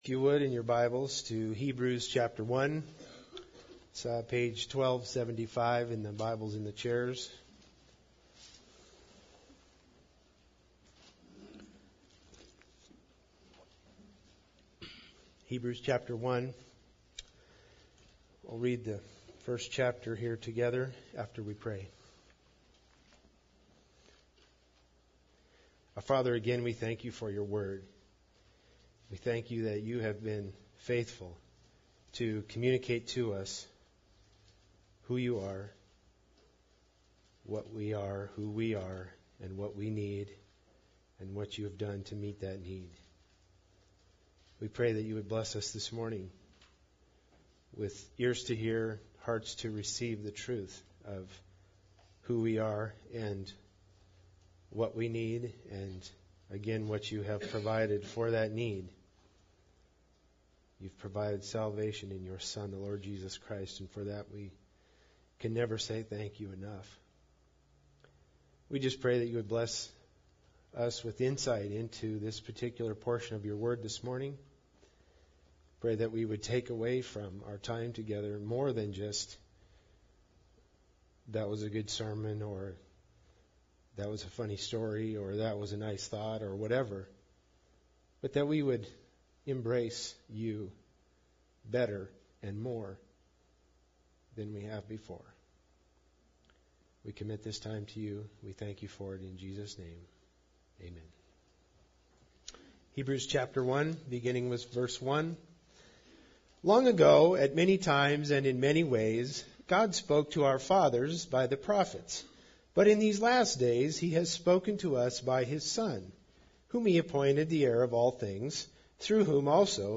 Hebrews 1:1-6 Service Type: Sunday Service Bible Text